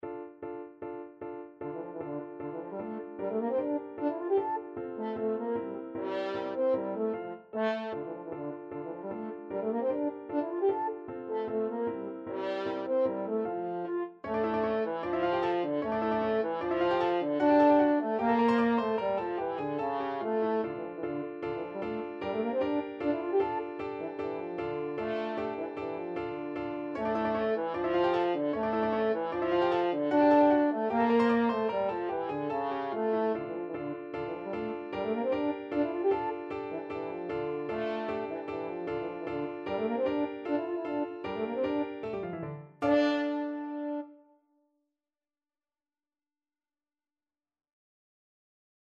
Classical Burgmuller, Friedrich Arabesque from 25 Progressive Pieces, Op.100 French Horn version
French Horn
D minor (Sounding Pitch) A minor (French Horn in F) (View more D minor Music for French Horn )
2/4 (View more 2/4 Music)
Allegro scherzando (=152) (View more music marked Allegro)
Classical (View more Classical French Horn Music)